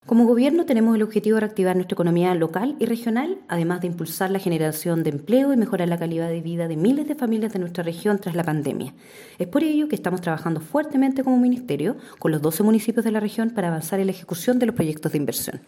A la ceremonia realizada en el Teatro Galia de la comuna de Lanco, asistieron el alcalde de la comuna de Lanco, Juan Rocha Aguilera, la Seremi de Desarrollo Social y Familia Srta. Ann Hunter Gutiérrez, Patricio Ordóñez, Coordinador (S) de Servicio Nacional del Adulto Mayor, (SENAMA), el Senador Alfonso de Urresti, los diputados Marcos Ilabaca e Iván Flores, junto al Core, Ítalo Martínez y la concejala, Pamela Ramírez.